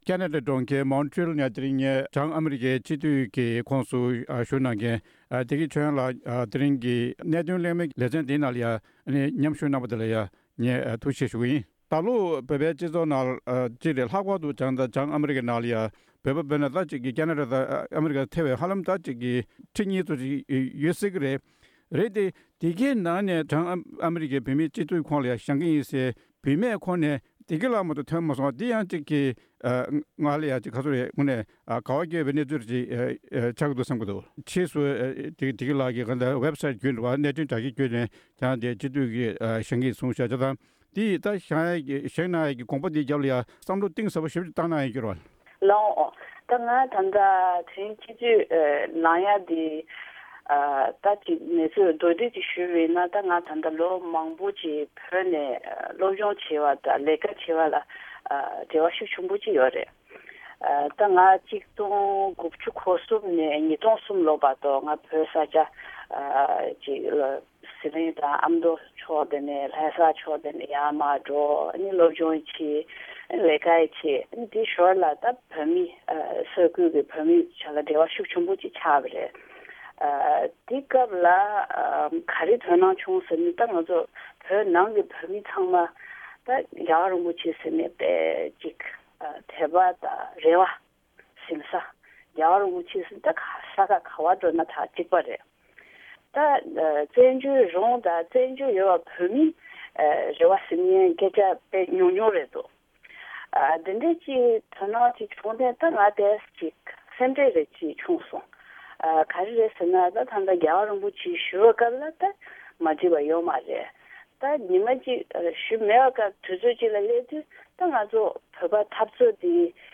ཞལ་པར་བརྒྱུད་བཀའ་འདྲི་ཞུས་པ་ཞིག་ལ་གསན་རོགས༎